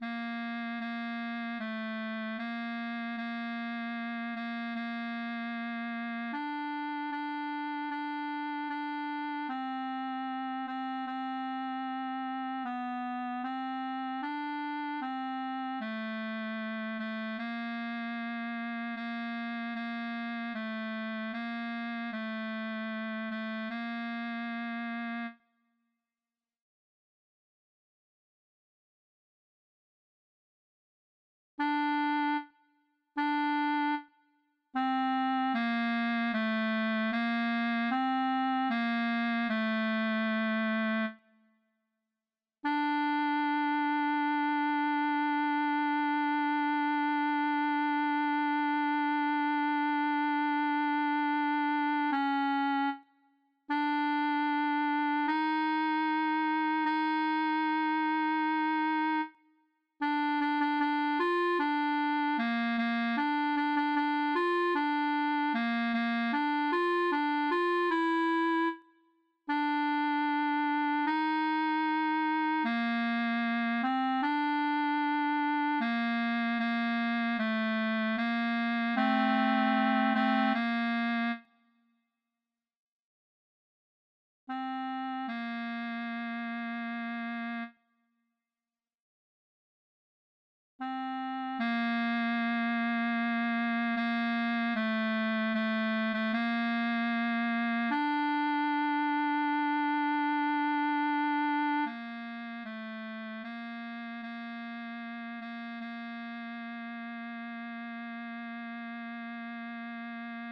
B=Harmony-for intermediate players